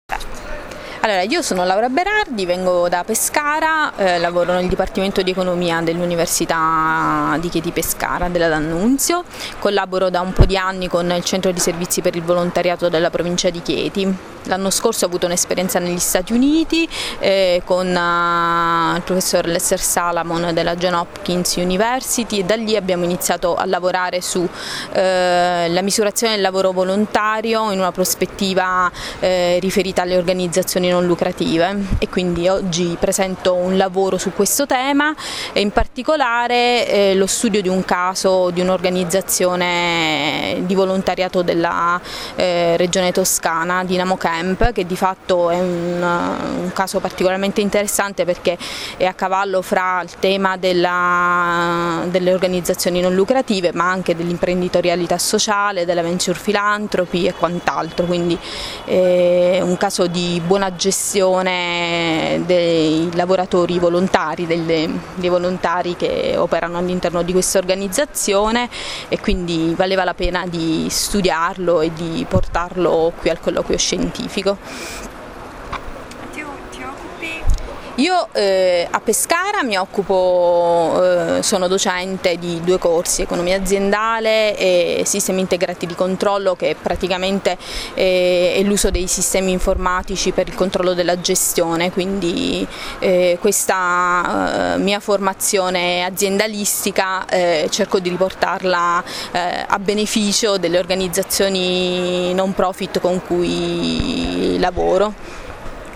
Il Colloquio Scientifico sull’impresa sociale, edizione VII, si è chiuso la scorsa settimana a Torino.
A ricordo di alcuni dei loro interventi, delle brevi audio interviste mordi e fuggi sui loro temi di ricerca.